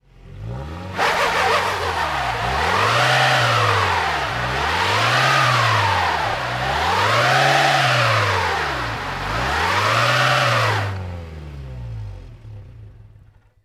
Car Tires Snow; Auto Stuck In Snow Tire Spins, Close Perspective 3x